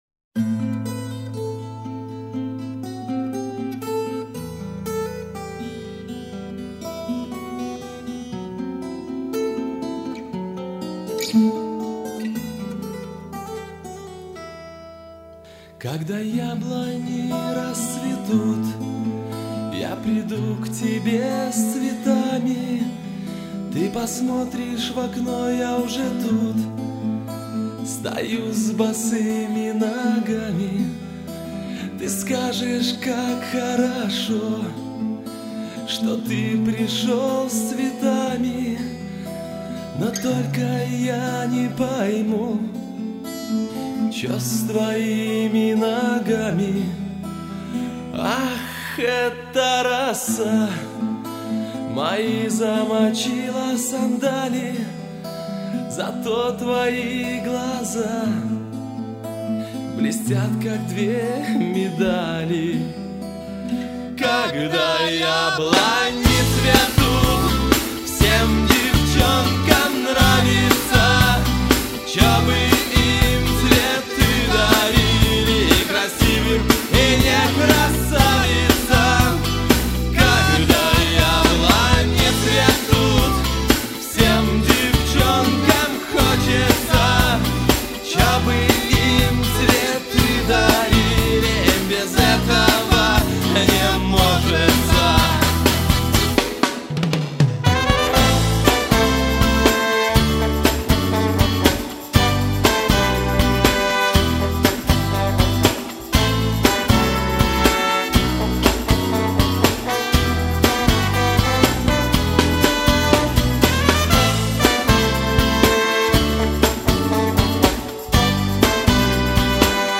Такт 4/4